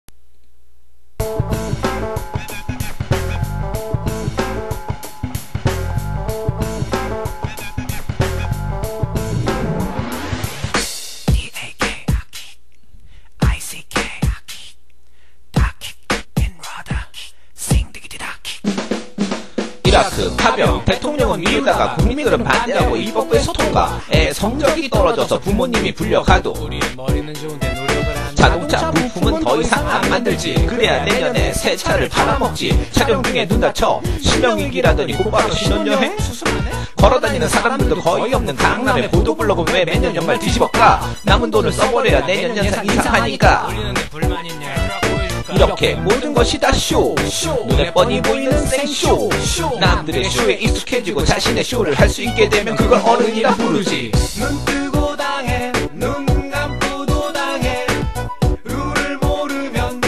첨부는 지난 겨울에 녹음했던 저의 노래
임산부 및 비위가 약하신 분들은 알아서 들으세요 ㅡ.ㅡ